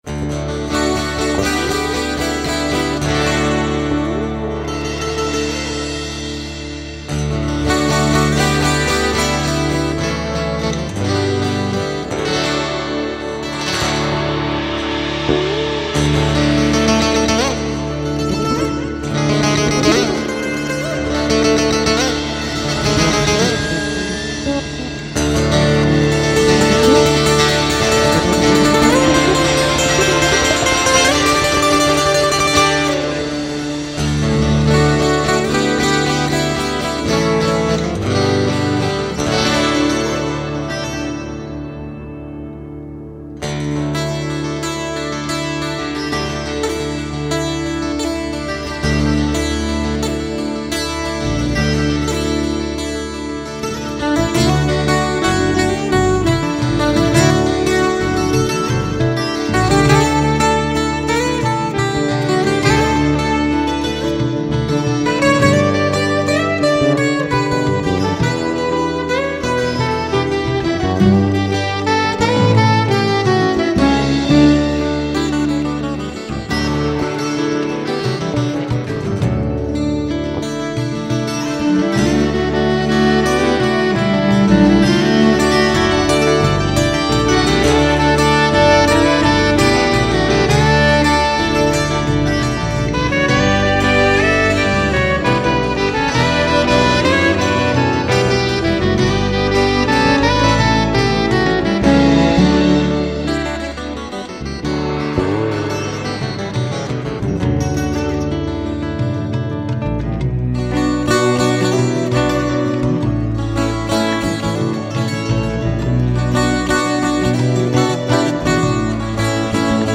2914   05:37:00   Faixa: 2    Mpb